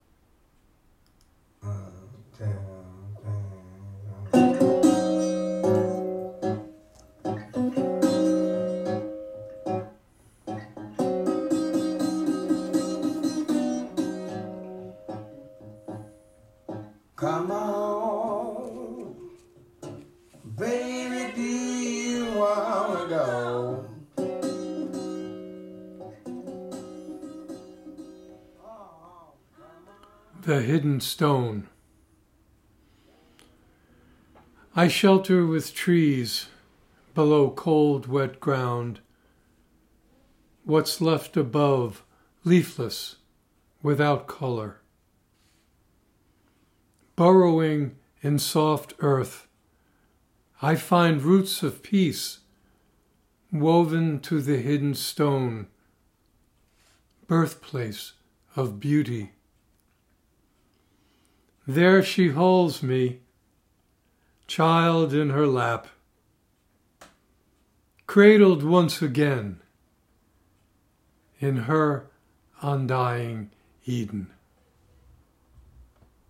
Reading of “The Hidden Stone” with music by Taj Mahal.